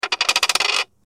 Coin Spin
coin_spin.mp3